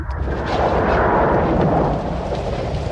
• WIND HOWLS Sound Effect.ogg
[wind-howls-sound-effect]-2_jqf.wav